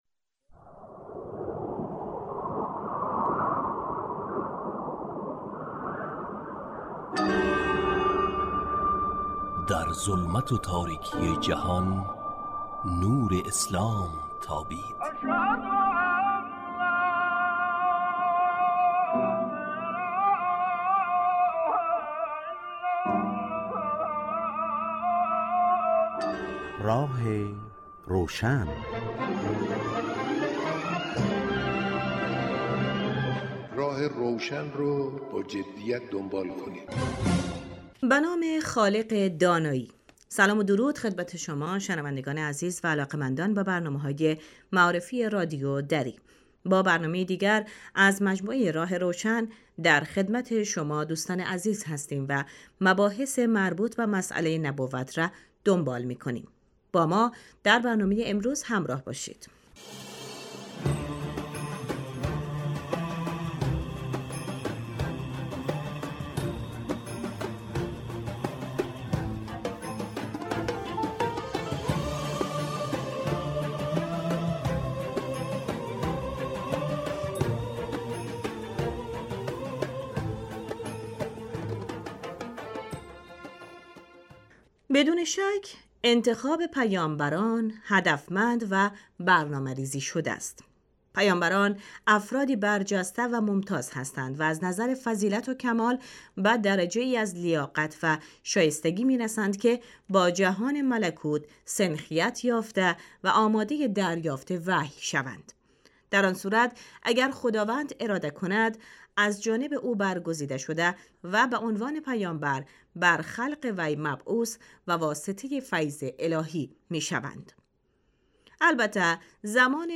کارشناس : حجت اسلام قرائتی